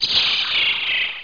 1 channel
bird_4.mp3